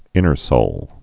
(ĭnər-sōl)